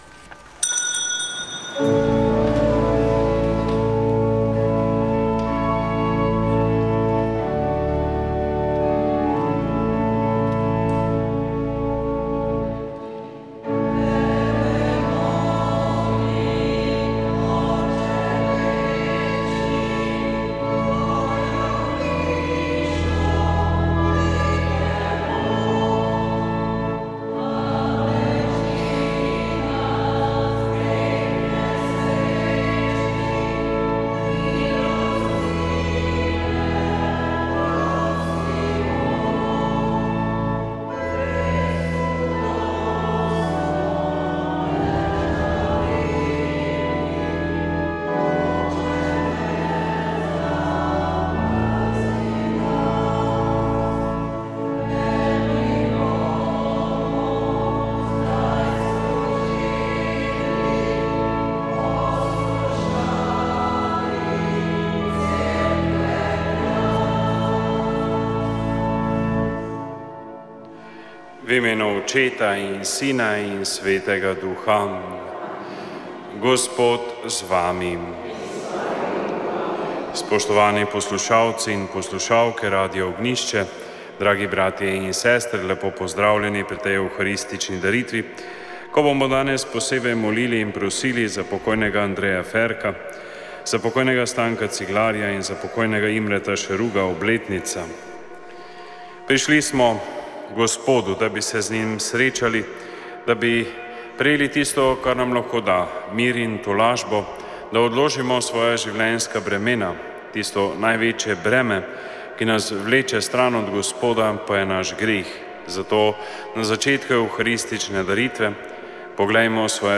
Sv. maša iz stolne cerkve sv. Janeza Krstnika v Mariboru 27. 2.